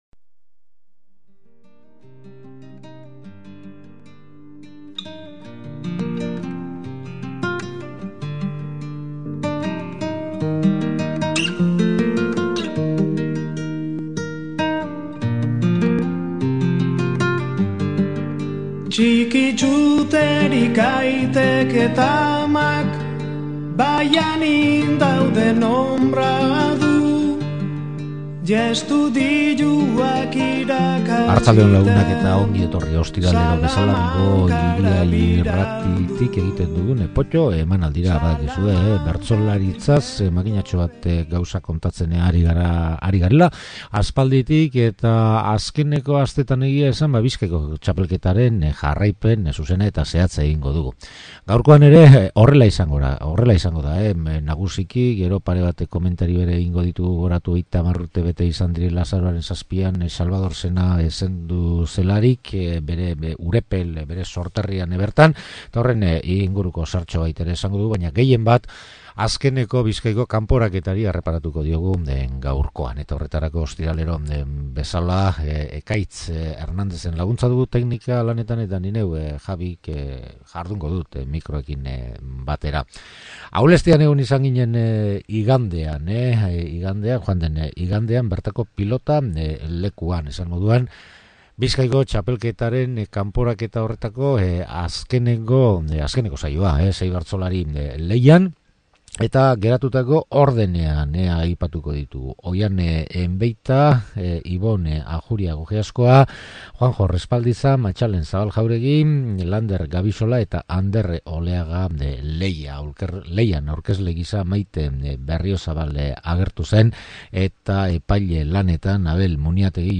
Joan den domekan Aulestian jokatutako Bizkaiko txapelketako azken kanporaketa saioari erreparatu diogu bertan entzundako bertsorik onenak nabarmenduz.
Eta asteartean Xalbador hil zenetik 30 urte igaro zirenez bere ahotsa errekuperatu dugu 68an Donostian egin saio batetik.